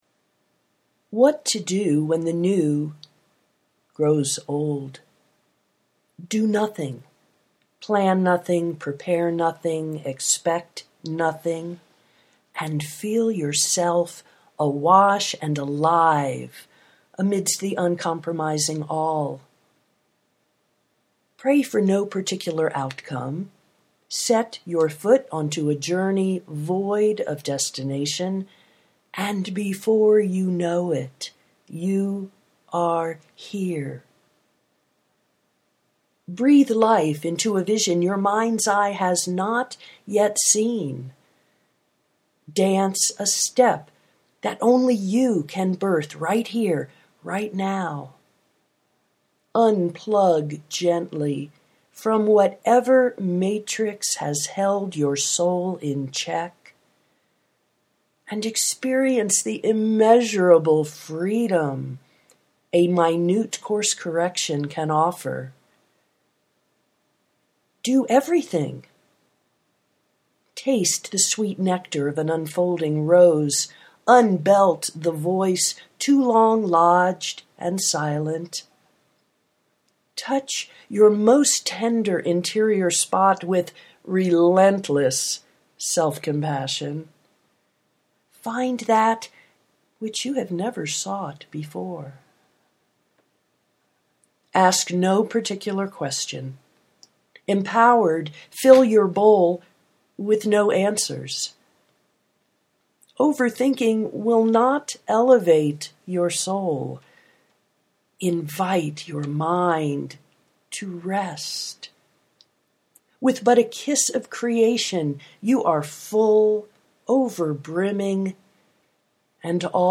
what to do when the new grows old (audio poetry 2:47)